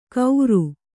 ♪ kauru